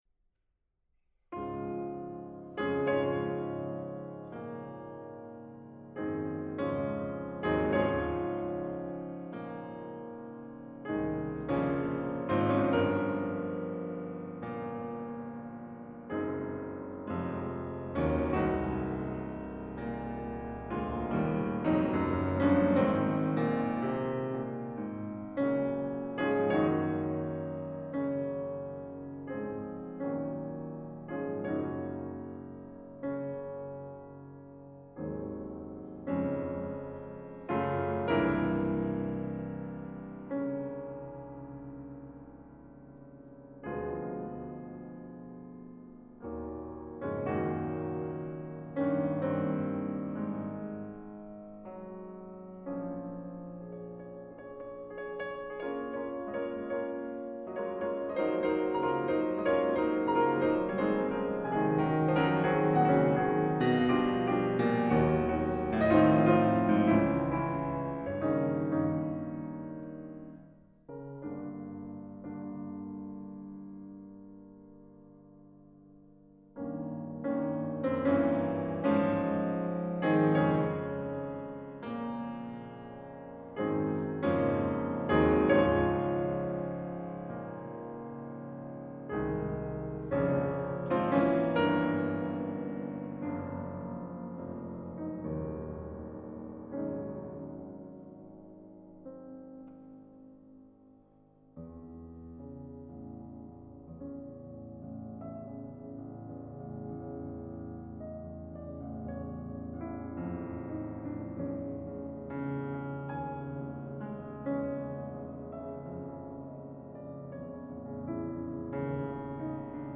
Solo instrument
Recorded in Hamburg, Germany.